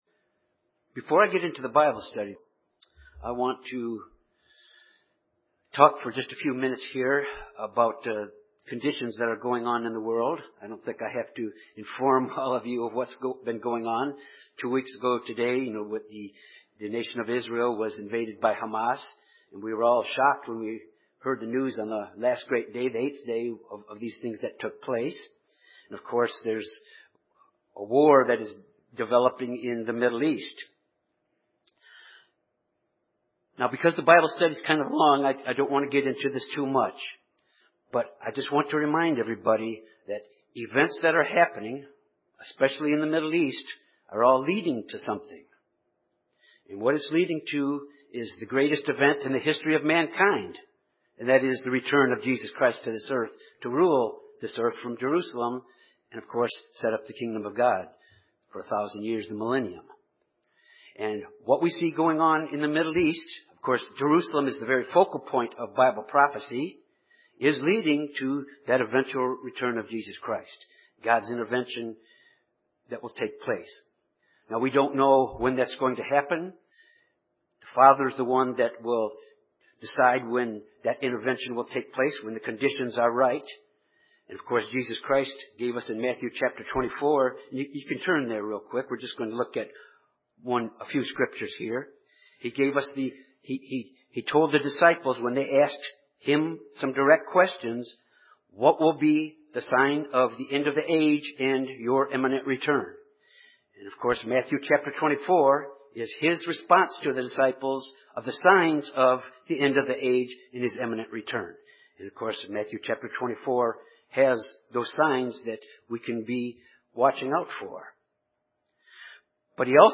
This Bible study of chapters 21-23 ends God’s judgment of foreign nations that had interactions with Israel and Juda which began in chapter 13.